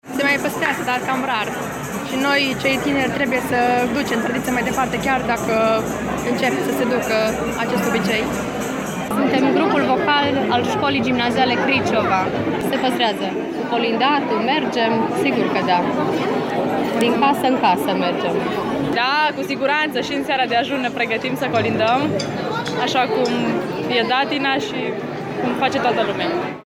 vox-alaiul-colindatorilor.mp3